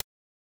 A rotary dial slider with realistic click sounds and haptic-like feedback.
click.ogg